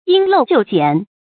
注音：ㄧㄣ ㄌㄡˋ ㄐㄧㄨˋ ㄐㄧㄢˇ
因陋就簡的讀法